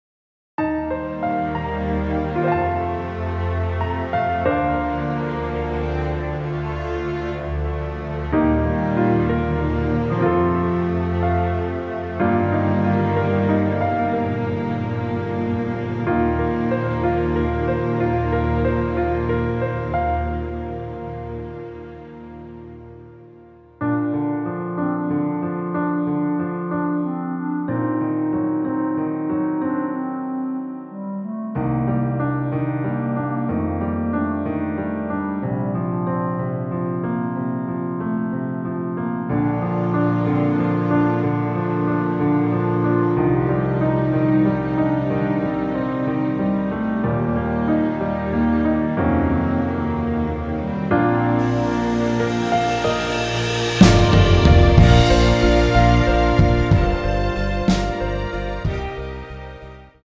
원곡보다 짧은 MR입니다.(아래 재생시간 확인)
원키에서(-3)내린 (짧은편곡)멜로디 포함된 MR입니다.
Bb
앞부분30초, 뒷부분30초씩 편집해서 올려 드리고 있습니다.